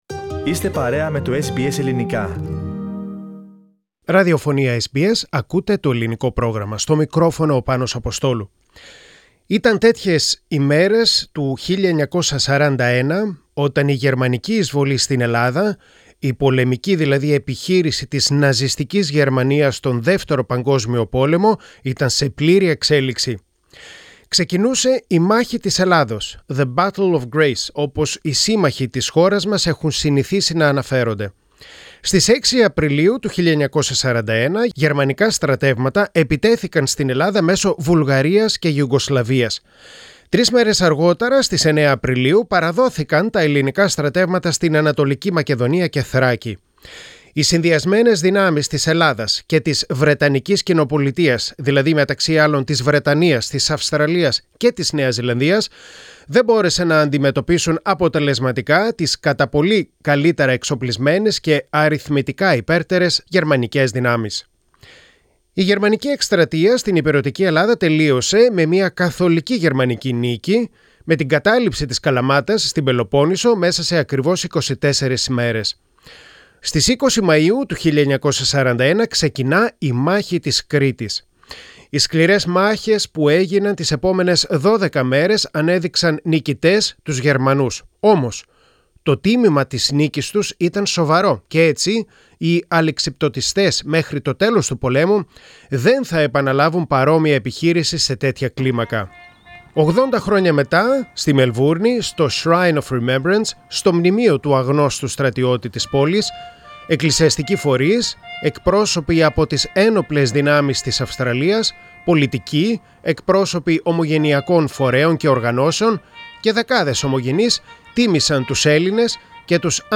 A commemorative service at Melbourne's Shrine of Remembrance, for the men and women from Australia and Greece, who fought and fell in the Battle of Crete.